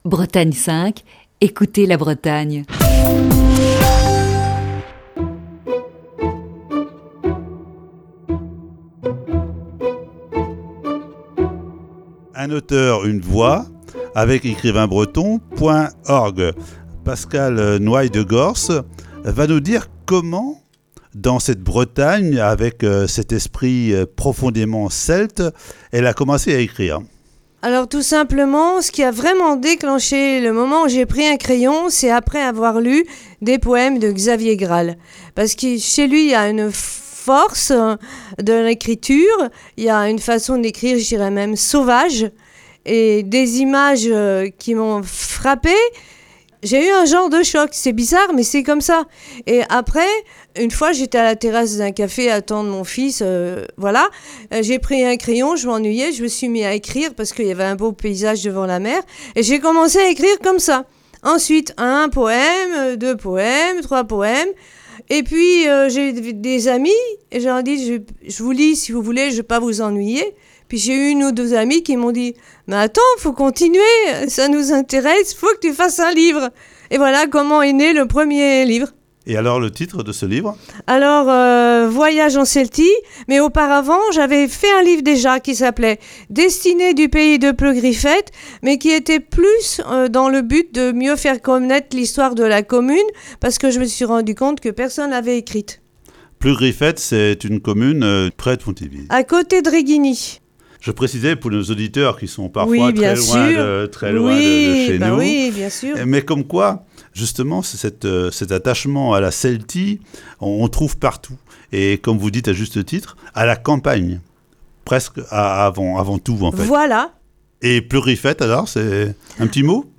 Voici la deuxième partie de cette série d'entretiens.